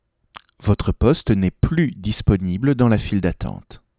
annonce-queuemember-off-8khz.wav